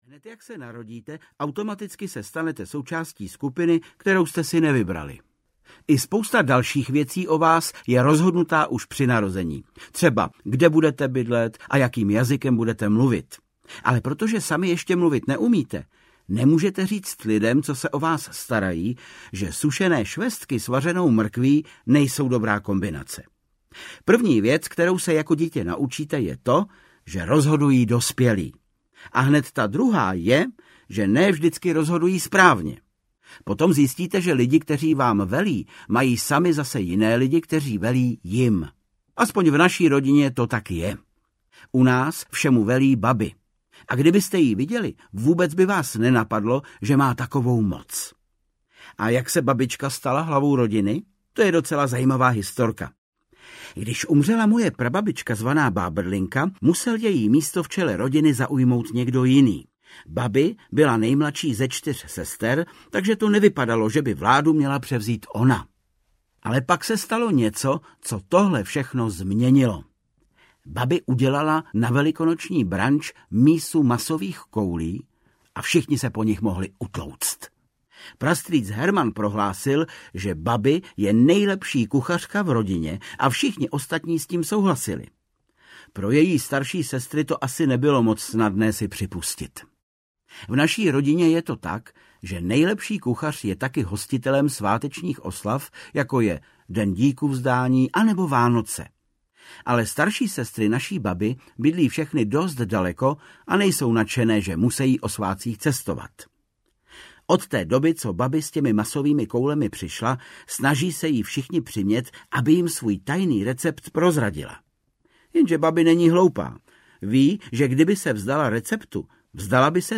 Deník malého poseroutky 19 - Recept na katastrofu audiokniha
Ukázka z knihy
• InterpretVáclav Kopta